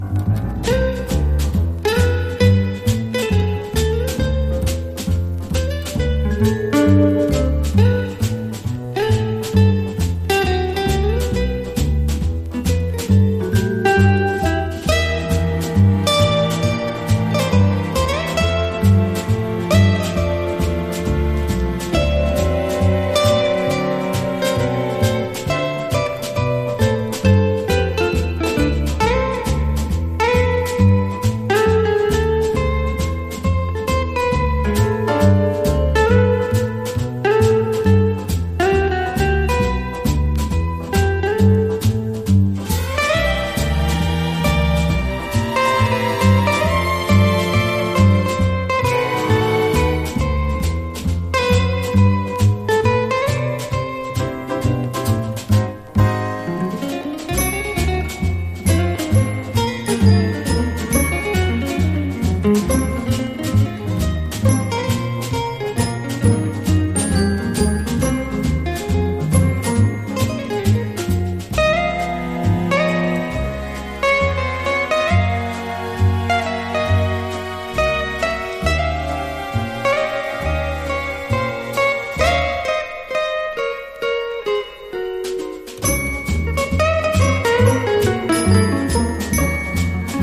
和ジャズ・ギターのトップ二人による1967年の共演盤！